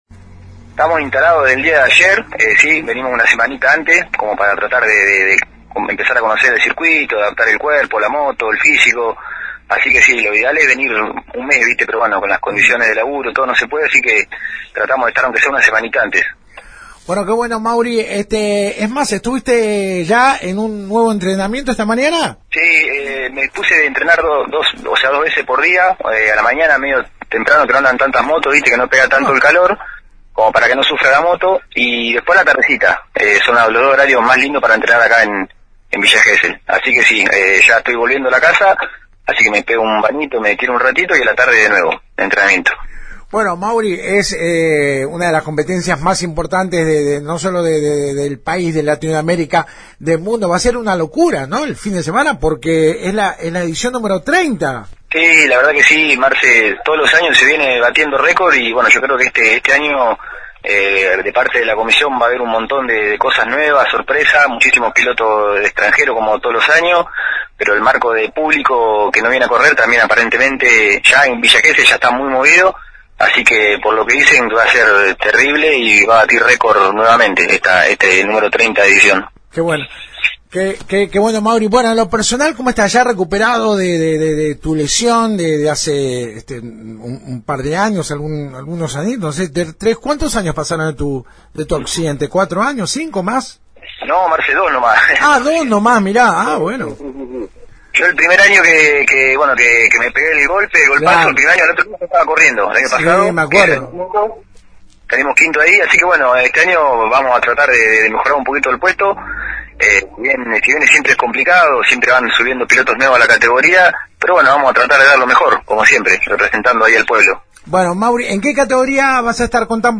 Lo hará en la categoría «Master B», según confirmó este martes en comunicación telefónica a FM Alpha desde la ciudad balnearia.